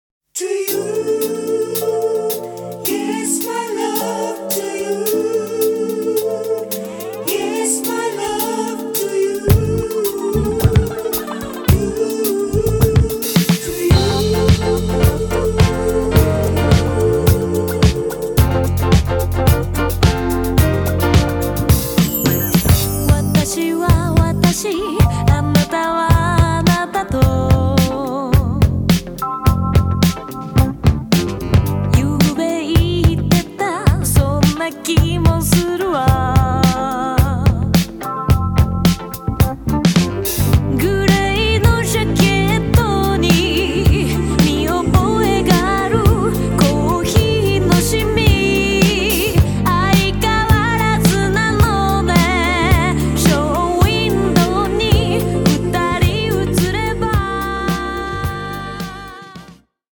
ジャンル(スタイル) JAPANESE POP CLASSIC / CITY POP